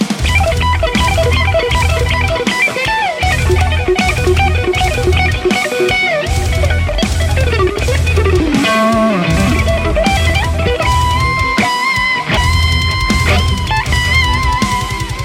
It's incredible sounding when pushed! Bright, edgy and alot of attack!
Lead Mix
RAW AUDIO CLIPS ONLY, NO POST-PROCESSING EFFECTS